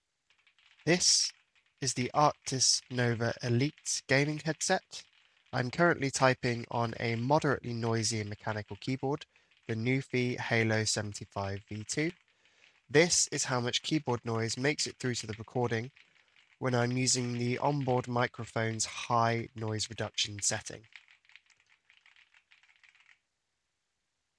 More importantly, in the second clip below (recorded using the High noise reduction setting), I typed with my NuPhy Halo75 V2 — a moderately loud keyboard — while talking. The keyboard noise is clearly audible.
Arctis Nova Elite Mic Test Mic NR 2.mp3